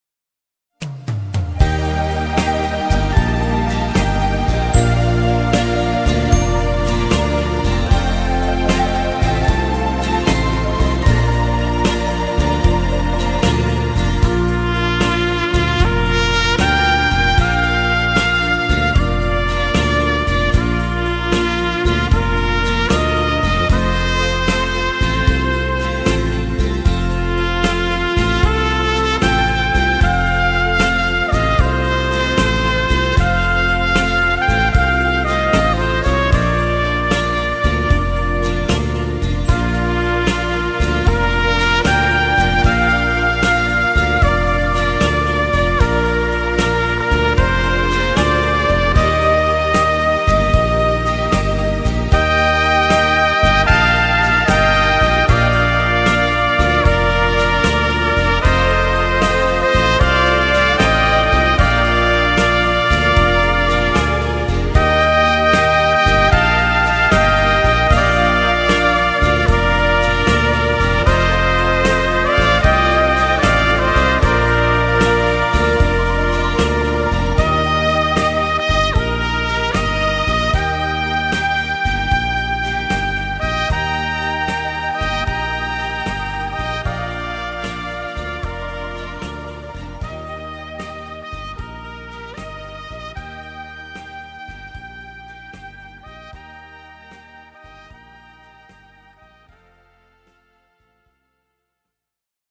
Trompeten-Solostück
Trompetensolo, daher kein Text.